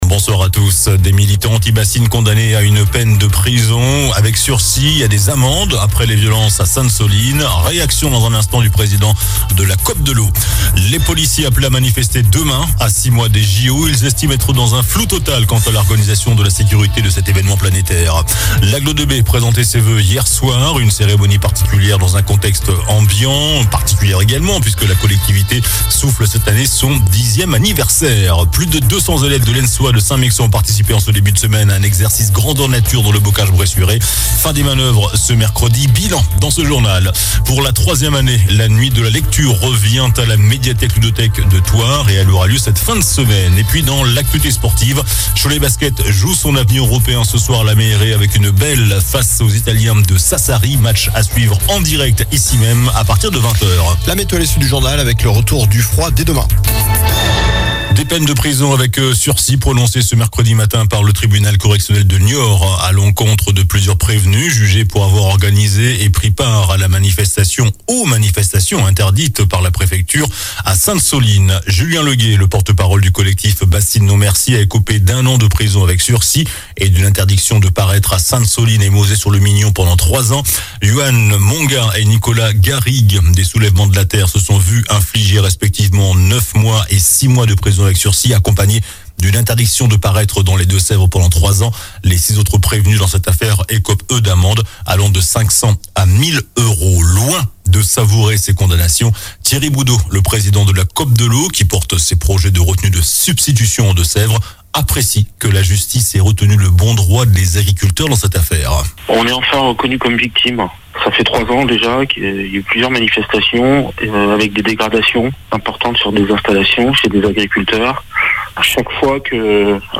JOURNAL DU MERCREDI 17 JANVIER ( SOIR )